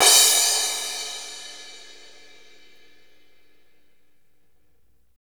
Index of /90_sSampleCDs/Northstar - Drumscapes Roland/CYM_Cymbals 1/CYM_F_S Cymbalsx